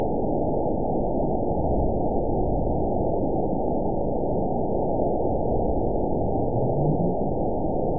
event 911973 date 03/13/22 time 22:29:15 GMT (3 years, 2 months ago) score 9.62 location TSS-AB04 detected by nrw target species NRW annotations +NRW Spectrogram: Frequency (kHz) vs. Time (s) audio not available .wav